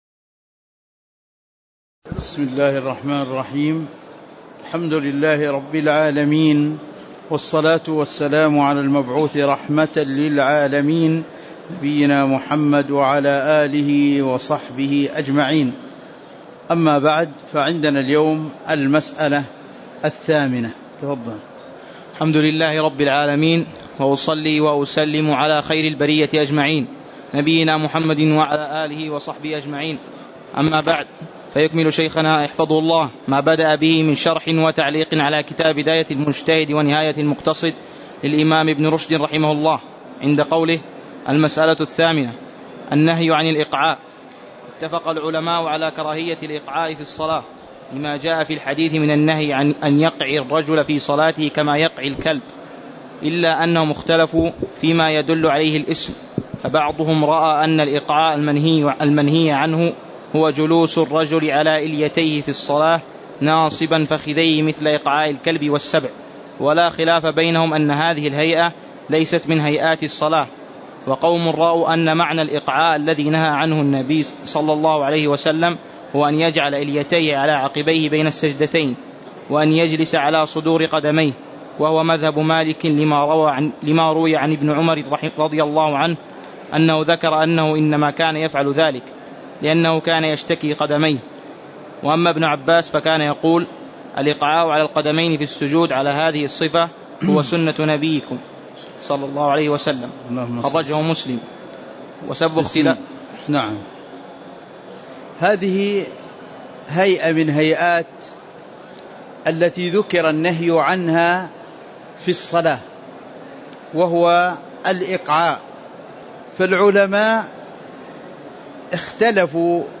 تاريخ النشر ٢٩ جمادى الآخرة ١٤٤١ هـ المكان: المسجد النبوي الشيخ